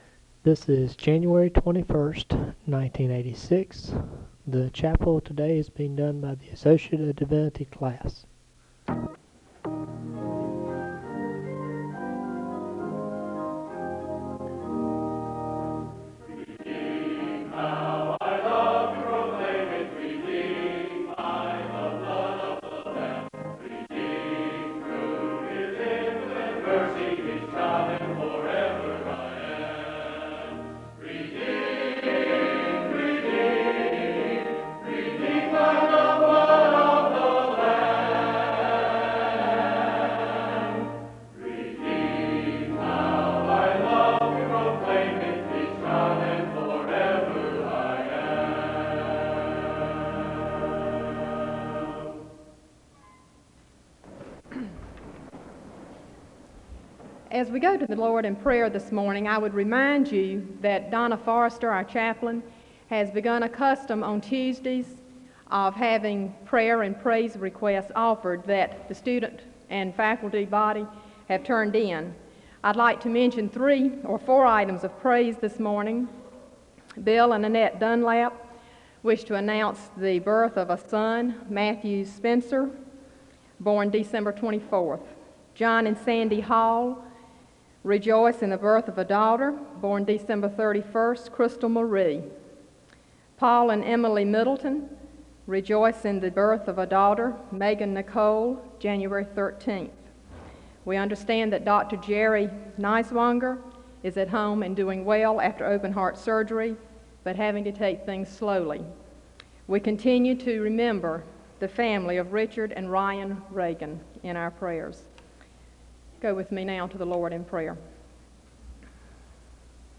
Download .mp3 Description The Associate of Divinity class conducts this chapel service. The service begins with the choir singing a song of worship (0:00-0:56). Prayer concerns are shared and there is a moment of prayer (0:57-4:39). There is a Scripture reading from Luke 9 (4:40-5:54).